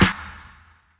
Claps
CLAPP.wav